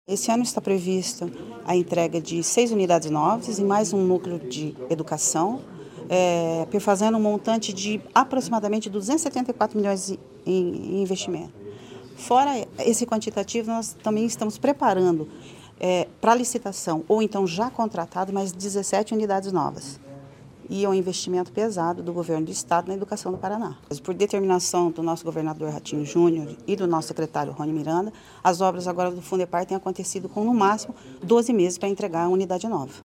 Sonora da diretora-presidente do Fundepar, Eliane Teruel Carmona, sobre o anúncio da construção de uma nova escola estadual em Maringá